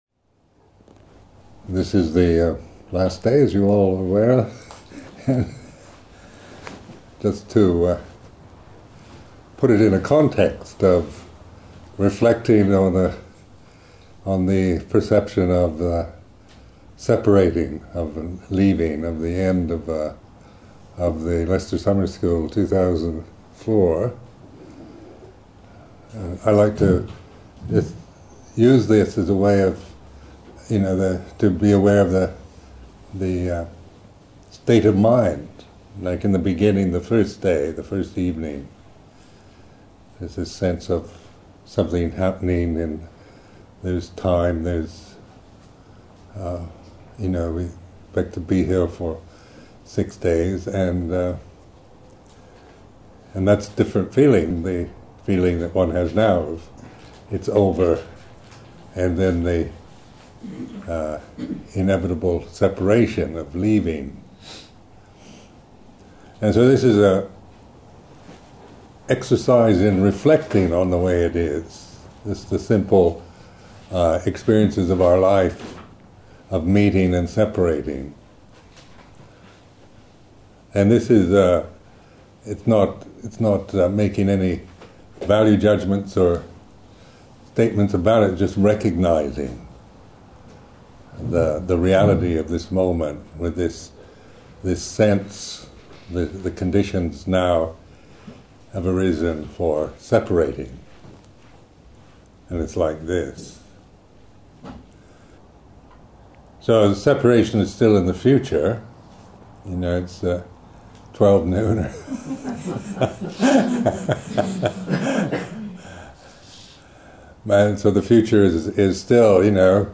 Buddhist talk: Being Awake to Rebirth in the Moment.
A talk given at the 2004 Buddhist Publishing Group Summer School in Leicester.